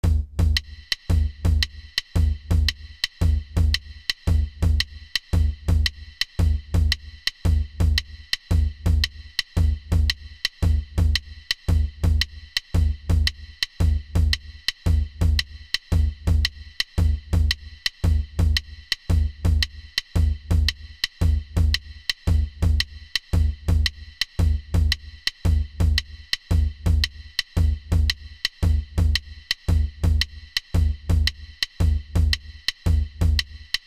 Rhythm Backup - Jig @ 85 bpm G Chords
bodhranjig85.mp3